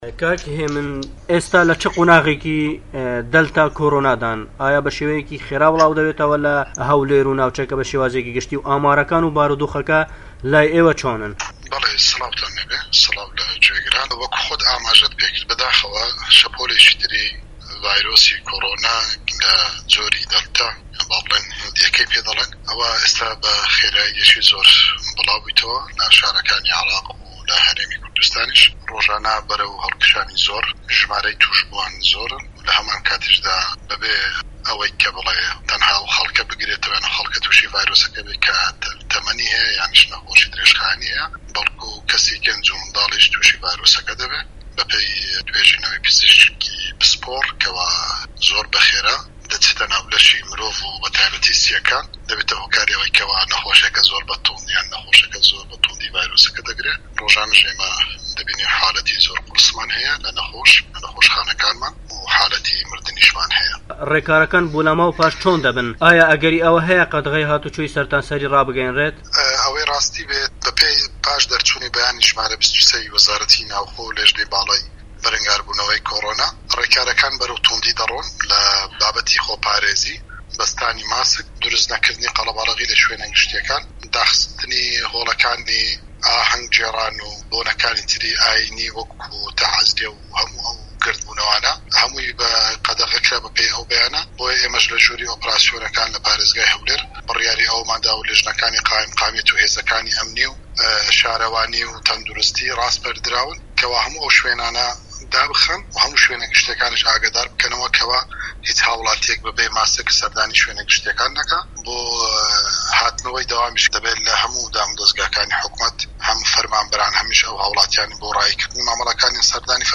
وتووێژ لەگەڵ هێمن قادر